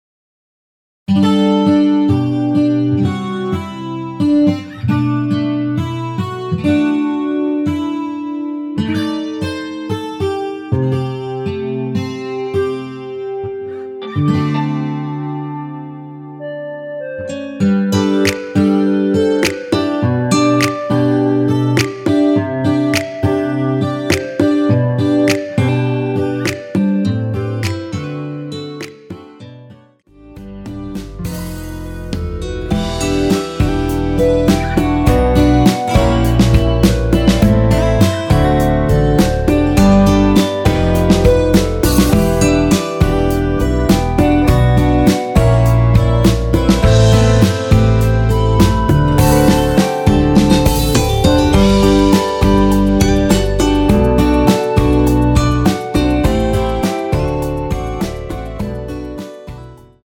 엔딩이 페이드 아웃이라 라이브 하시기 좋게 엔딩을 만들어 놓았습니다.
원키에서(+4)올린 멜로디 포함된 MR입니다.
앞부분30초, 뒷부분30초씩 편집해서 올려 드리고 있습니다.
중간에 음이 끈어지고 다시 나오는 이유는